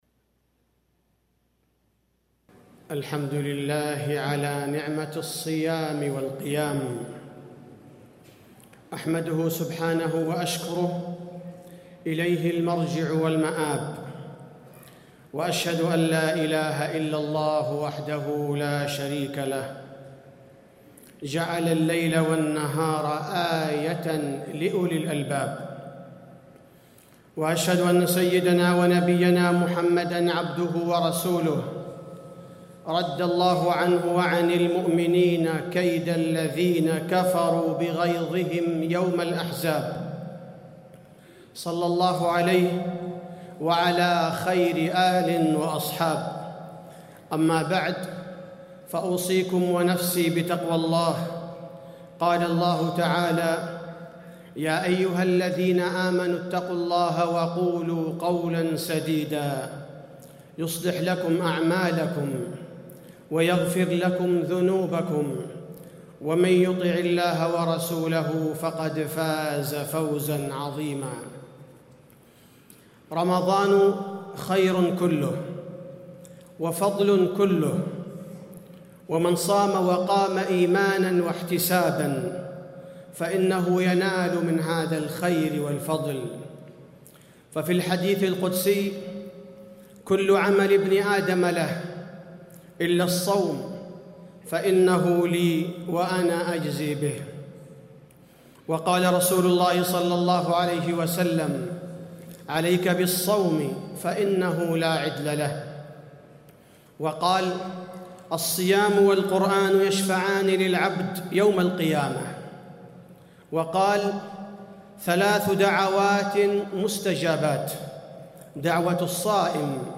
تاريخ النشر ١٠ رمضان ١٤٣٤ هـ المكان: المسجد النبوي الشيخ: فضيلة الشيخ عبدالباري الثبيتي فضيلة الشيخ عبدالباري الثبيتي شهر رمضان وغزوة الأحزاب